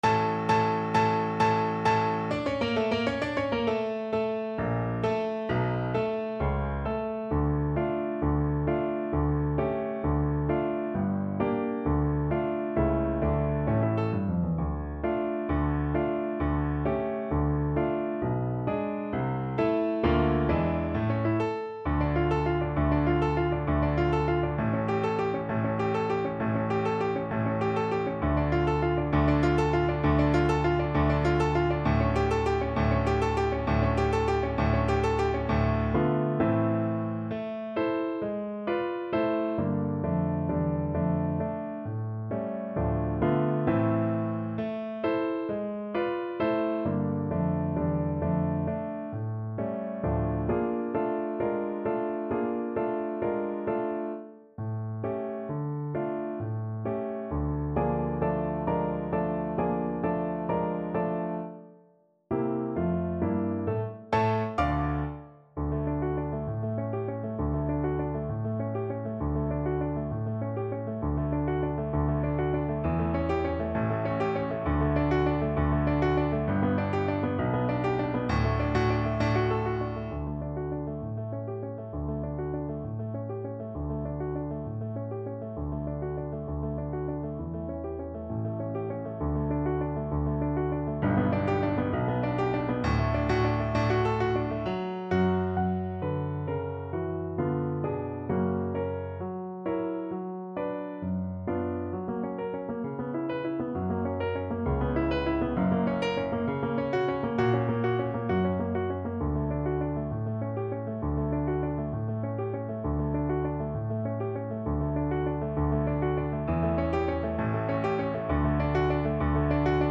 Play (or use space bar on your keyboard) Pause Music Playalong - Piano Accompaniment Playalong Band Accompaniment not yet available reset tempo print settings full screen
6/8 (View more 6/8 Music)
D minor (Sounding Pitch) (View more D minor Music for Violin )
~. = 132 Allegro con spirito (View more music marked Allegro)
Classical (View more Classical Violin Music)